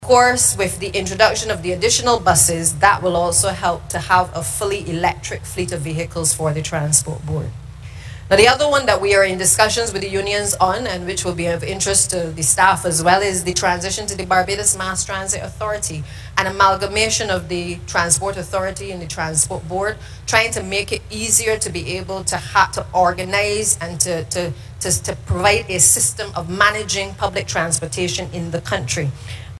This announcement was made by the Deputy Prime Minister and Minister of Transport, Works, and Water Resources, Santia Bradshaw at the Transport Board 67th Anniversary Church Service at the Church of the Nazarene, Upper Collymore Rock, St Michael.
Minister of Transport, Works and Water Resources, Santia Bradshaw